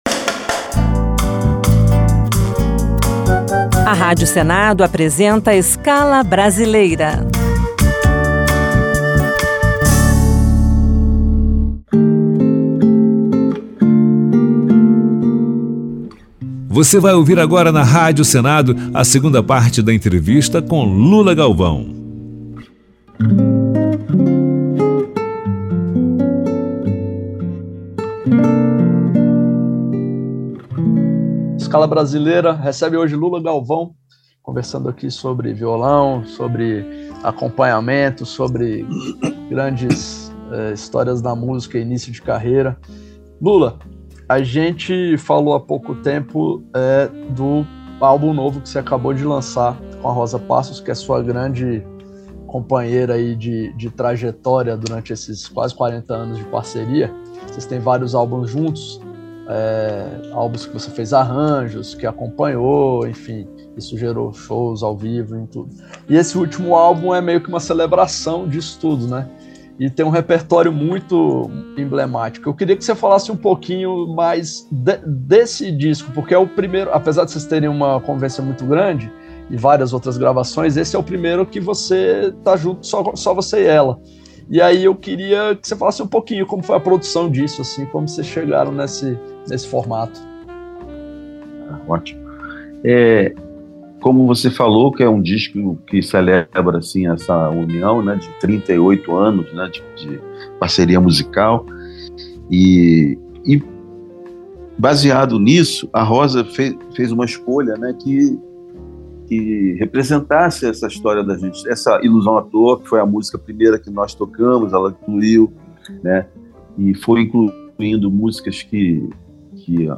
Escala Brasileira